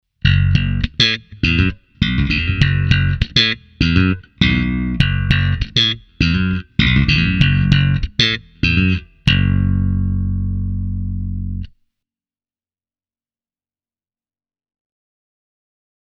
Tältä basso kuulostaa soitettuna SansAmp Bass Driver DI:n läpi:
molemmat mikrofonit – släppaus
lakland-skyline-44-60-both-pus-slap.mp3